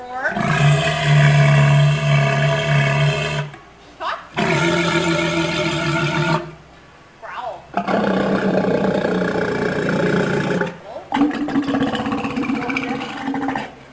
Хриплые звуки моржа в зоопарке